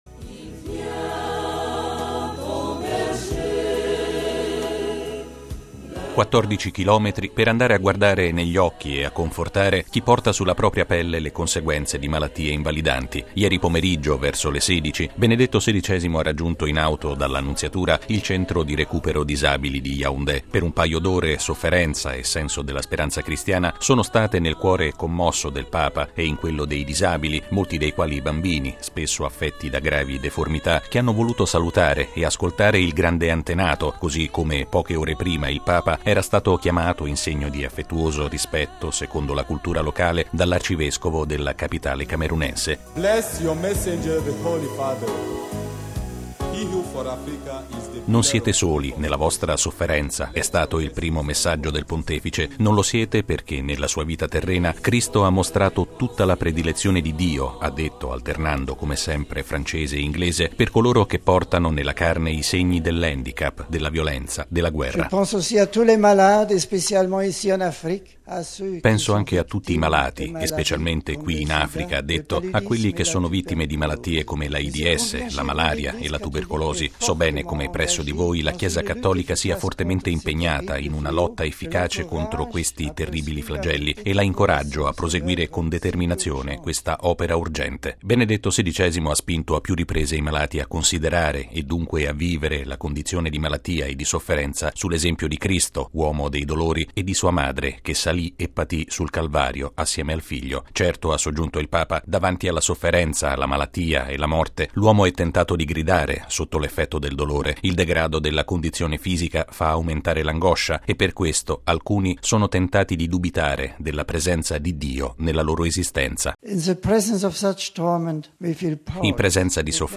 (canto)
“Non siete soli” nella vostra sofferenza, è stato il primo messaggio del Pontefice: non lo siete perché nella sua vita terrena Cristo ha mostrato tutta la predilezione di Dio - ha detto, alternando come sempre francese e inglese - per coloro portano “nella loro carne” i segni dell’handicap, della violenza, della guerra: